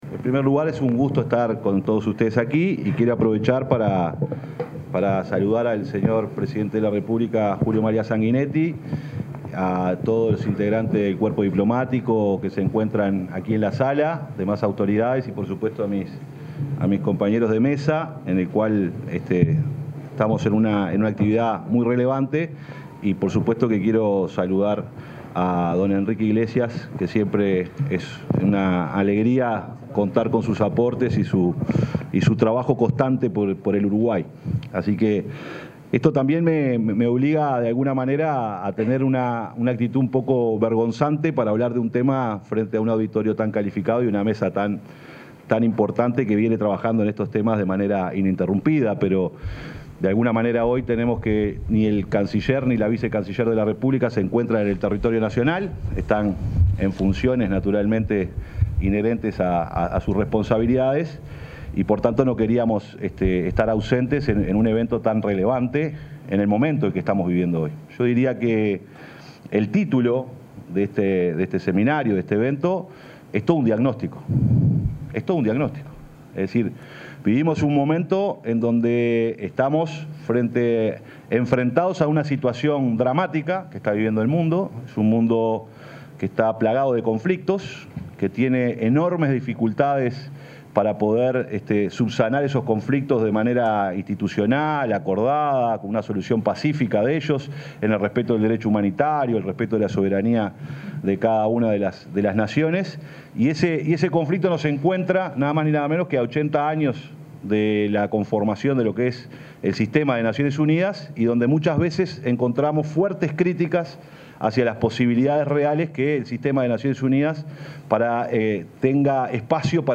Palabras del secretario de Presidencia, Alejandro Sánchez
Se realizó el seminario internacional Alternativas Urgentes para el Multilateralismo.